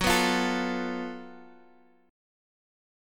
F#mM7bb5 chord